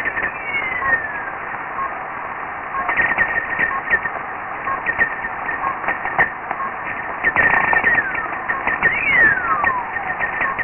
Mars Microphone: Test Sound Data (Whistler)
whistler.wav